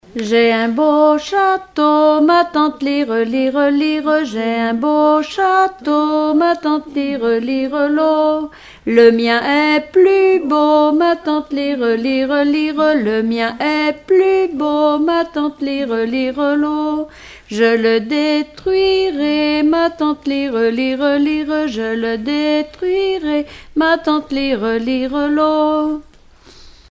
rondes enfantines
Collectif-veillée (2ème prise de son)
Pièce musicale inédite